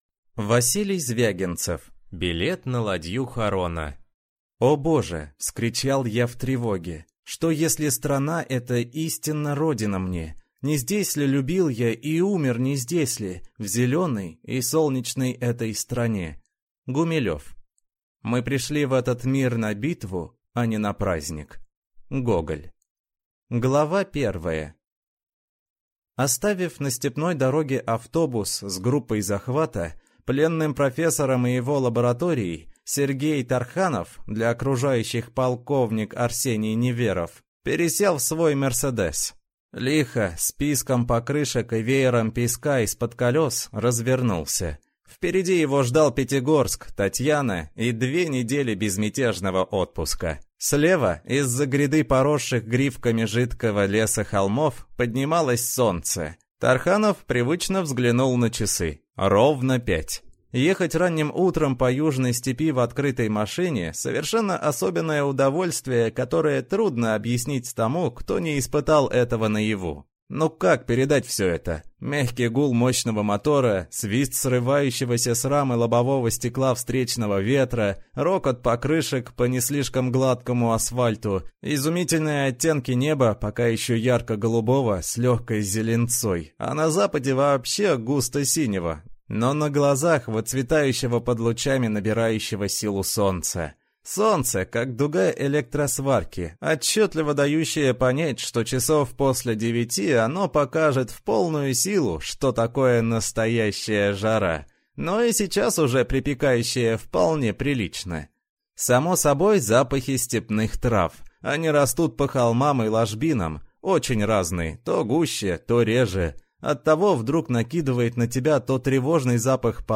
Аудиокнига Билет на ладью Харона | Библиотека аудиокниг